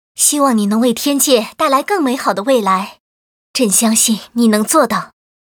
文件 文件历史 文件用途 全域文件用途 Erze_tk_04.ogg （Ogg Vorbis声音文件，长度5.5秒，93 kbps，文件大小：63 KB） 源地址:地下城与勇士游戏语音 文件历史 点击某个日期/时间查看对应时刻的文件。